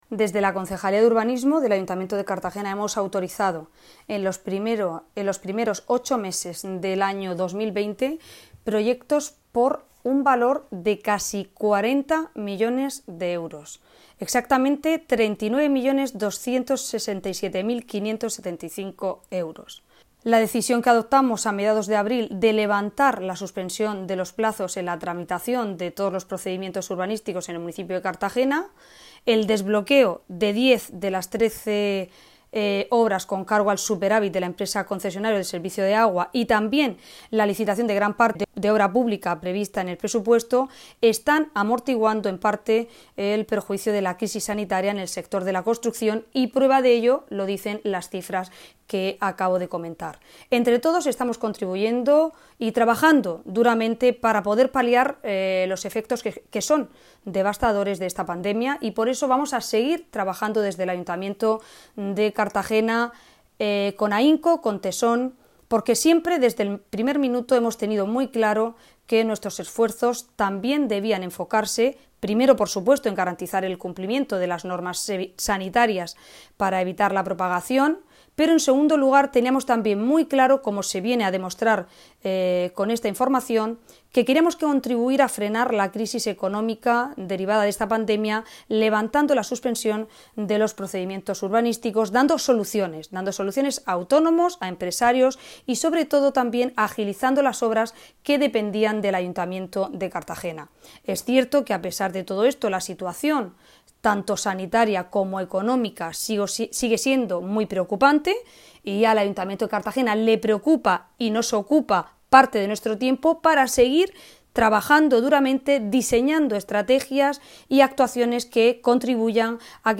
Enlace a Declaraciones de la alcaldesa, Ana Belén Castejón, sobre proyectos autorizados por Urbanismo